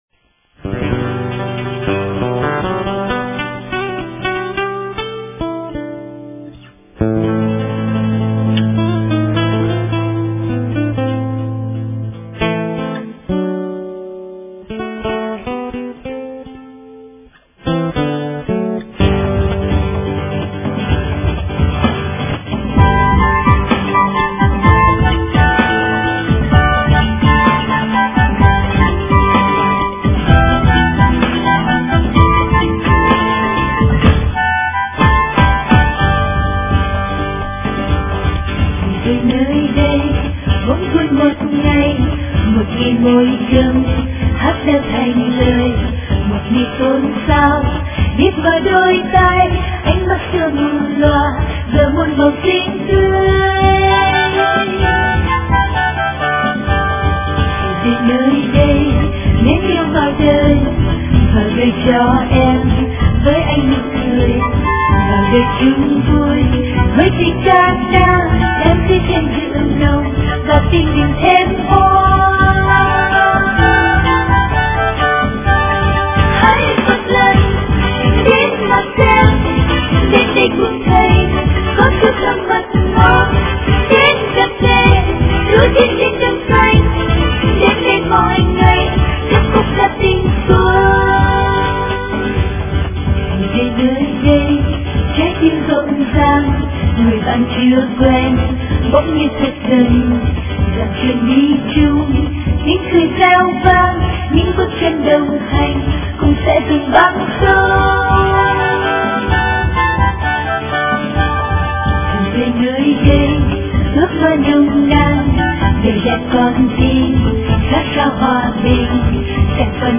Dòng nhạc : Ngợi ca Thiên Chúa